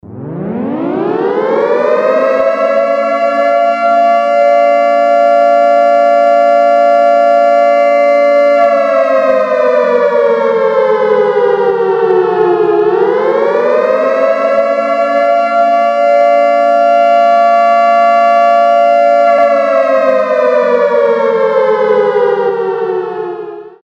Рингтоны » 3d звуки » Сирена ядерной опасности